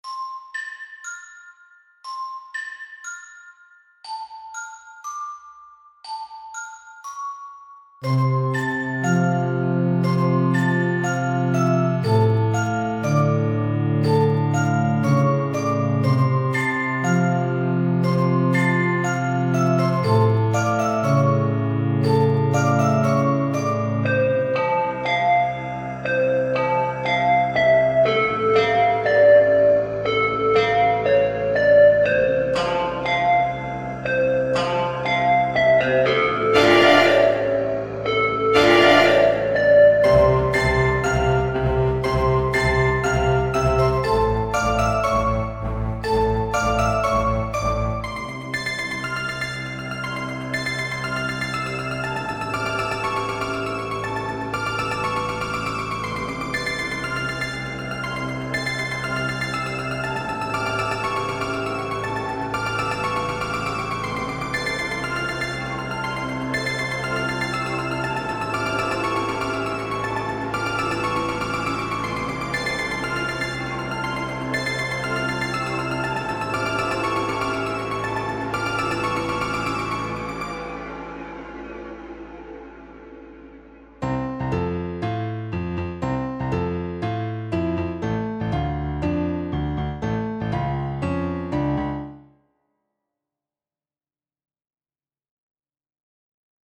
I made a song a while ago to emulate how a nightmare feels.